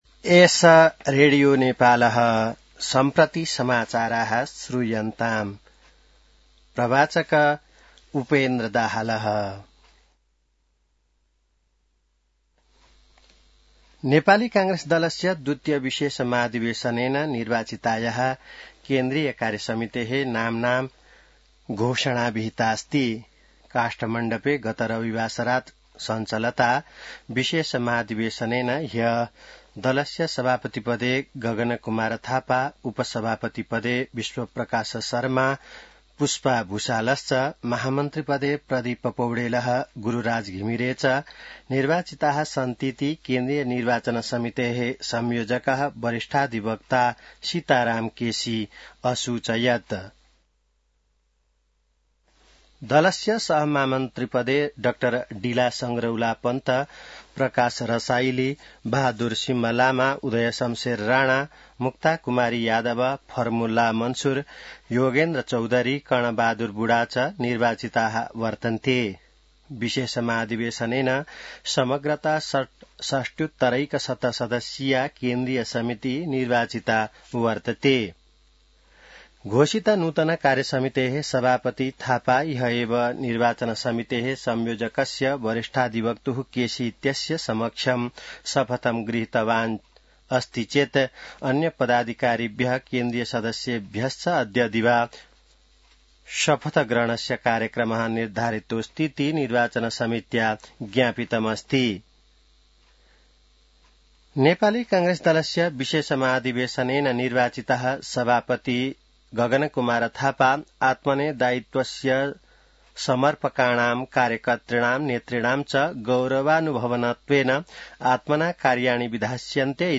An online outlet of Nepal's national radio broadcaster
संस्कृत समाचार : १ माघ , २०८२